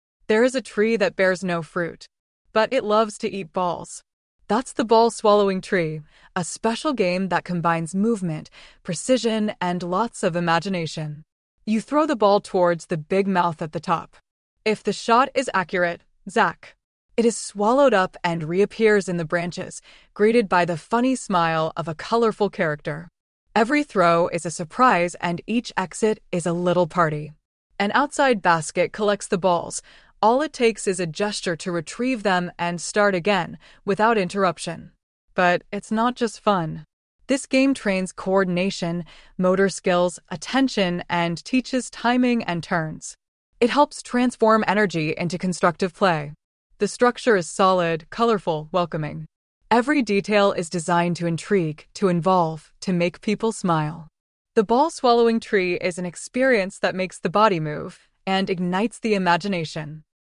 The ball is thrown with the aim of scoring through the top of the tree; it will exit from the surrounding branches accompanied by the greeting of the corresponding colorful caricature.